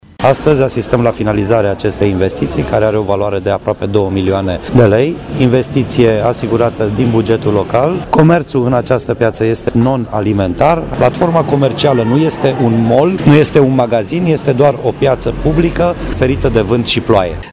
La inaugurare a fost prezent și administratorul public al municipiului Brașov, Miklos Gantz: